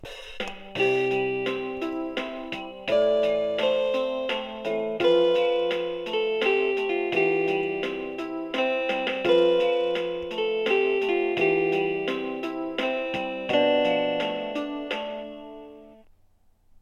• 36 vyzváněcí tónů k výběru, ukázky zvonění: